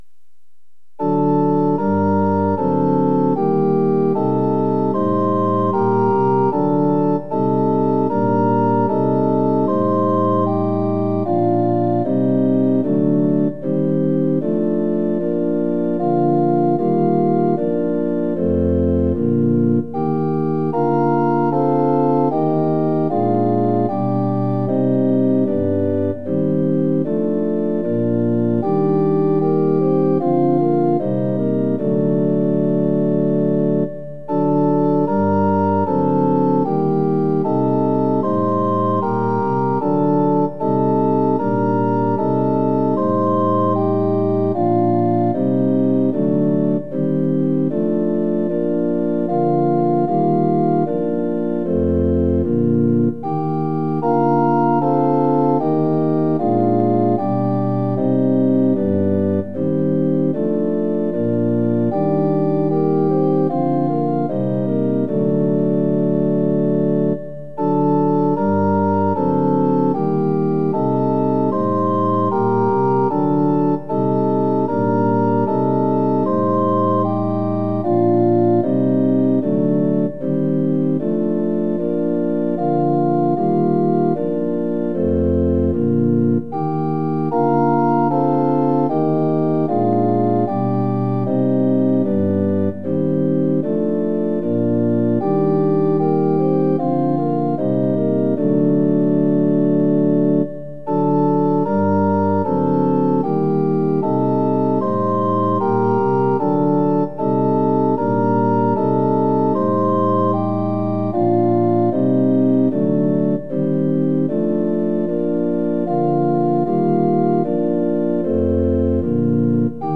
◆　４分の４拍子：　４拍目から始まります。